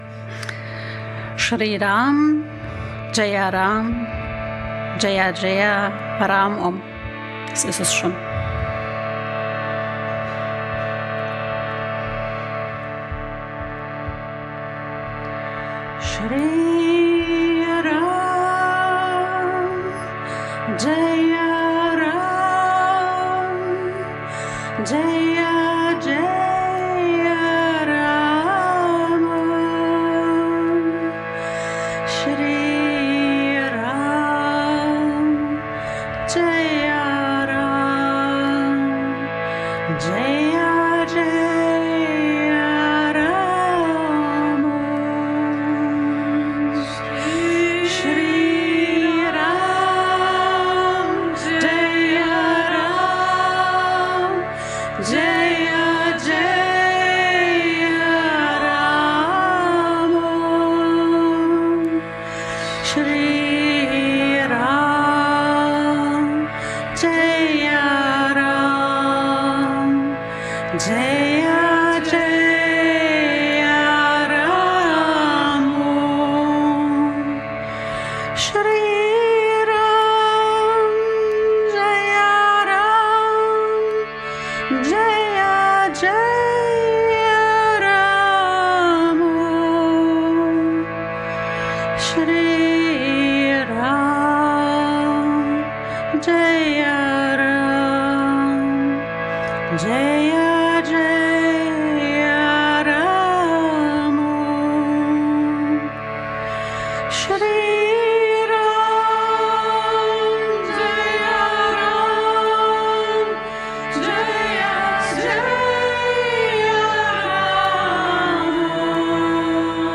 Kirtan and Stotra: Sanskrit Chants Podcast